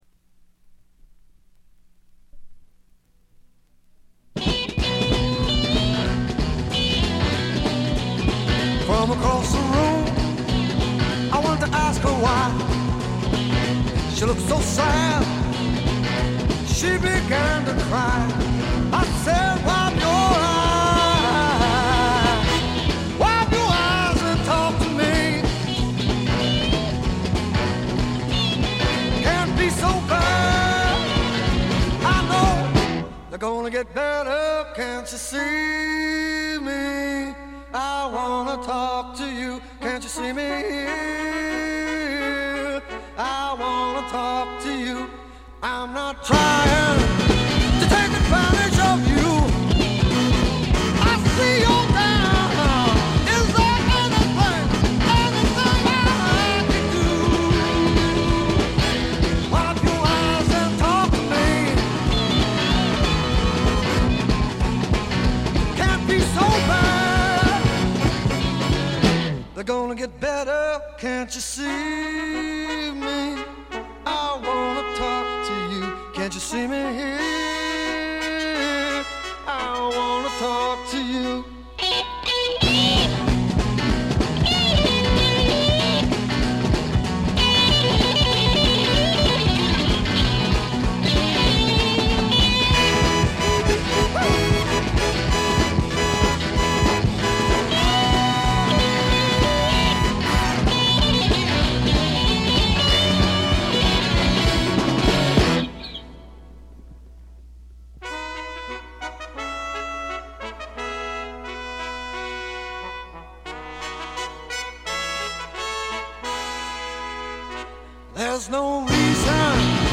試聴曲は現品からの取り込み音源です。